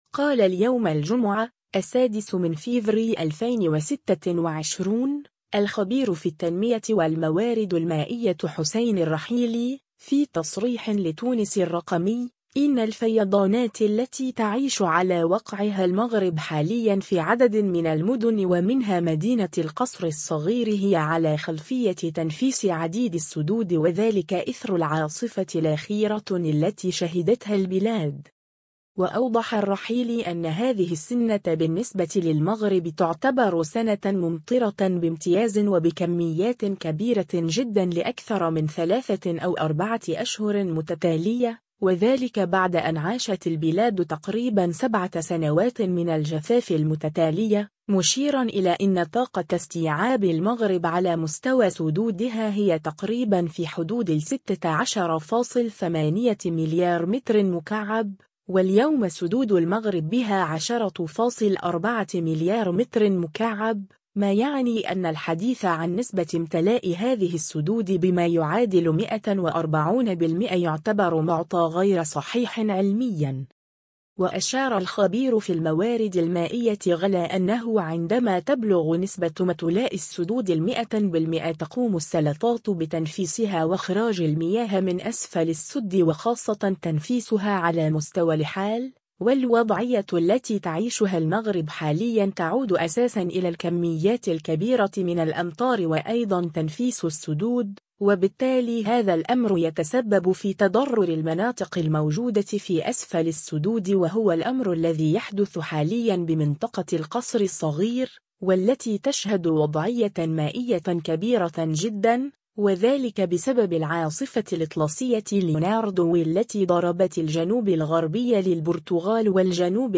في تصريح لتونس الرّقمي